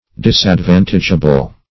disadvantageable - definition of disadvantageable - synonyms, pronunciation, spelling from Free Dictionary
Search Result for " disadvantageable" : The Collaborative International Dictionary of English v.0.48: Disadvantageable \Dis`ad*van"tage*a*ble\, a. Injurious; disadvantageous.